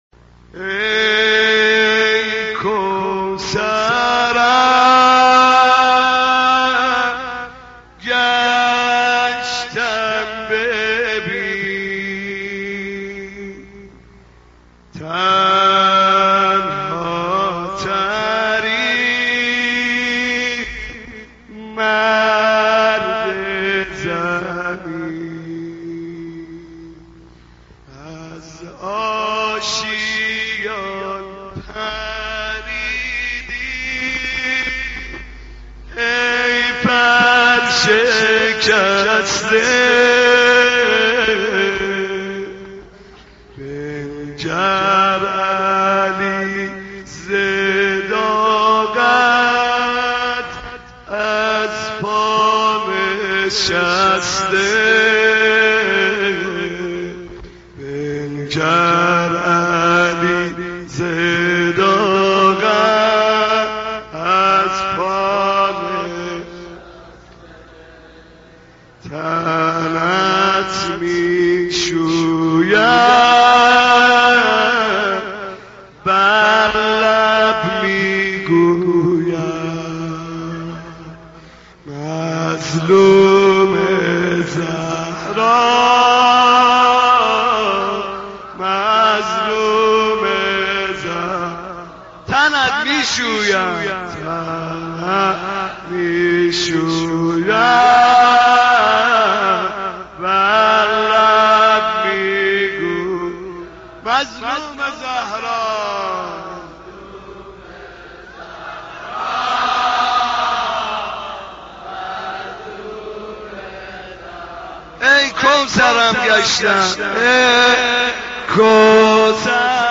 دانلود مداحی ای کوثر گشتم ببین تنهاترین مرد زمین - دانلود ریمیکس و آهنگ جدید
سینه زنی شهادت حضرت فاطمه کبری(س)